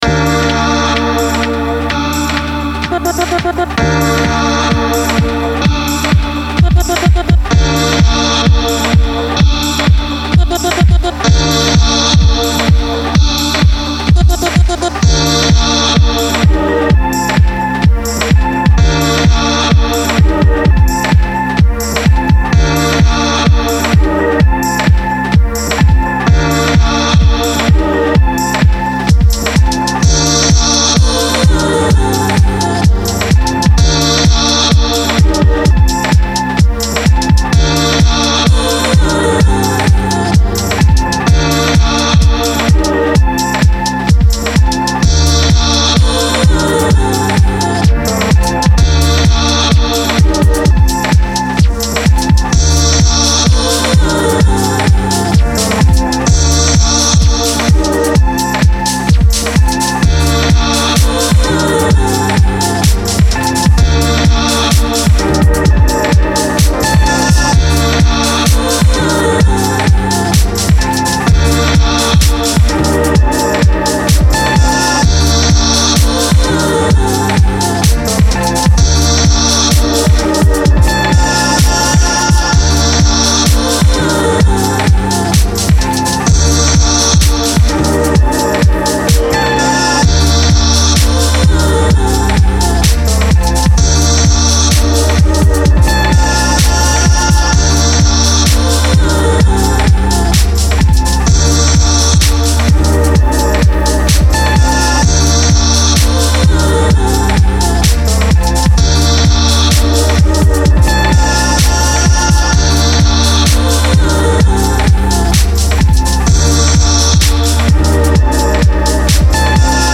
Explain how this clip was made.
Ableton, Maschine, MicroBrute, 404SX compressor (sorry again) circa 2018: